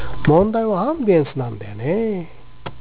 LIVE1 AT GRAND CENTRAL
violin.au